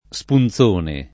spunzone [ S pun Z1 ne ]